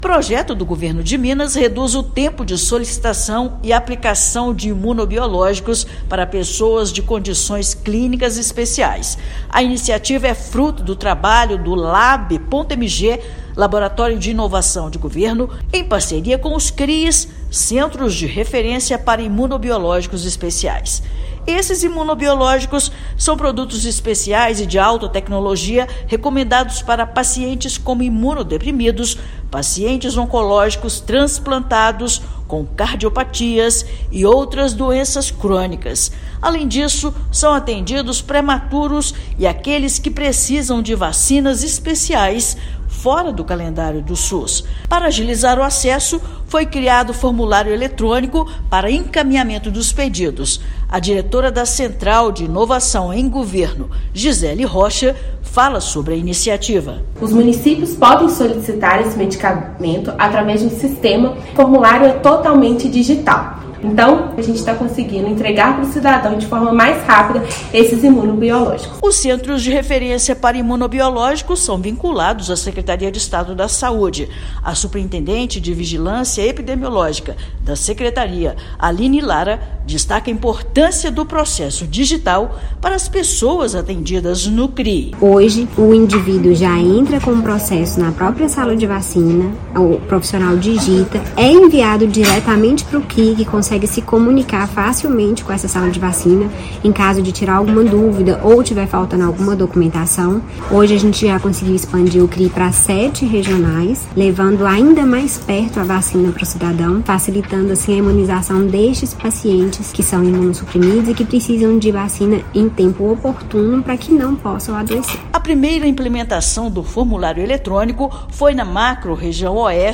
[RÁDIO] Governo de Minas agiliza acesso a vacinas e imunobiológicos especiais, beneficiando pacientes como transplantados e prematuros